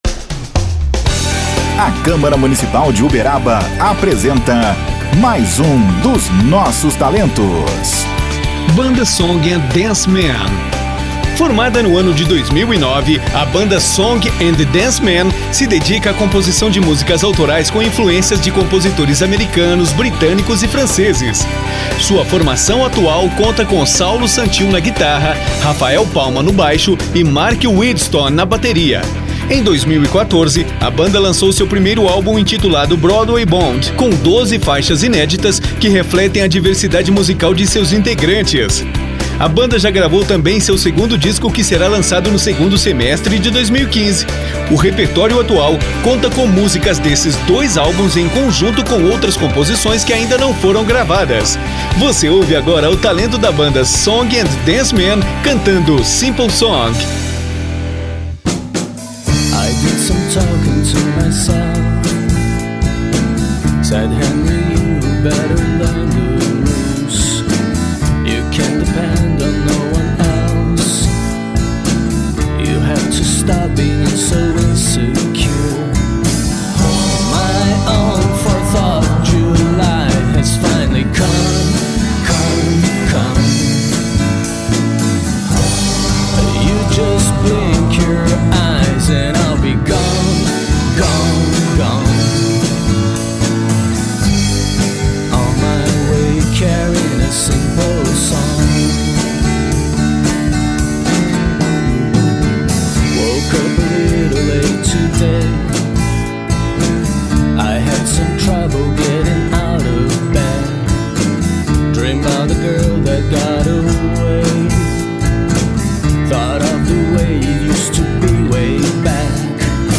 guitarra
baixo
bateria